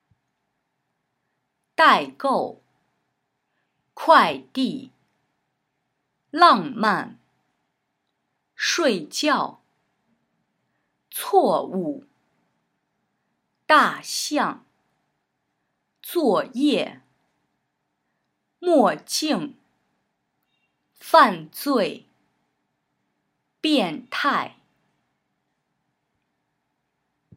Chinesische Aussprache
Übung 19: Töne 4+4